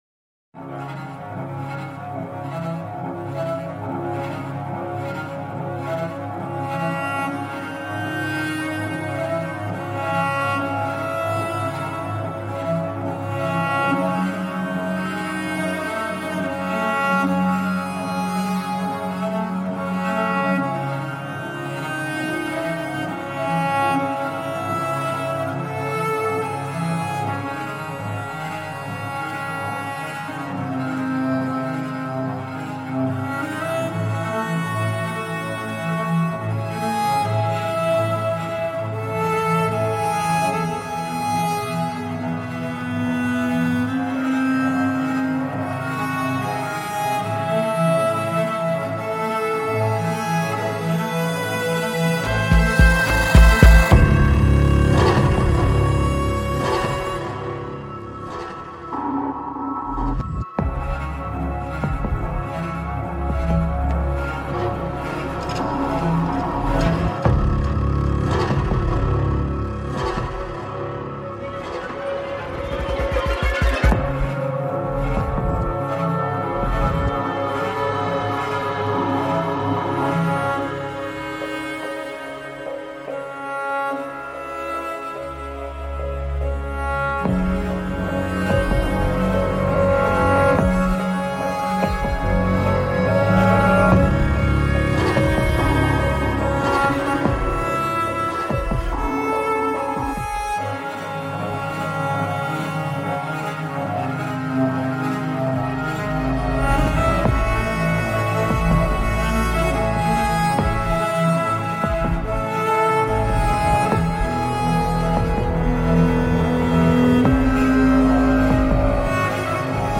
viole de gambe